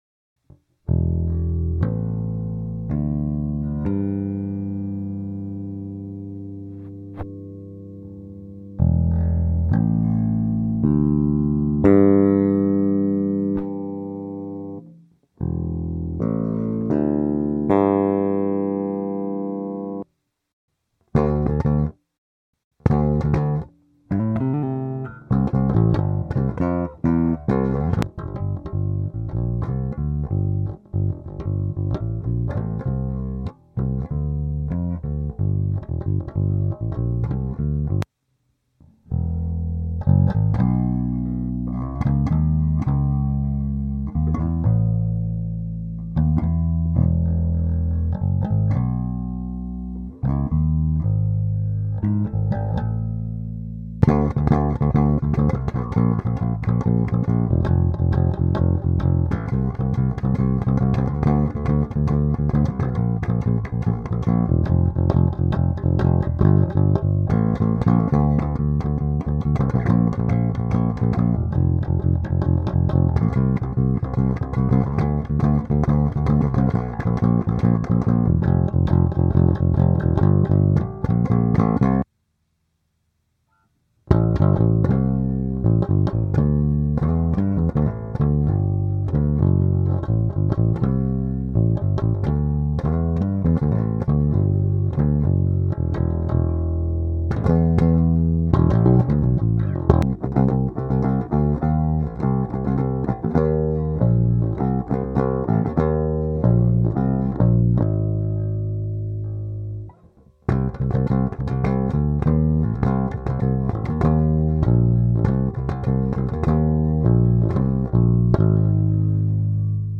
The pickups Alnico 5 magnets are paired with Neodymium bars adding gauss to the alnico magnets providing the pickup with the power needed for a fuller, fatter tone.
Alneo Thunderbird pickup
Alneo-Thunder-bird-sound-clip.mp3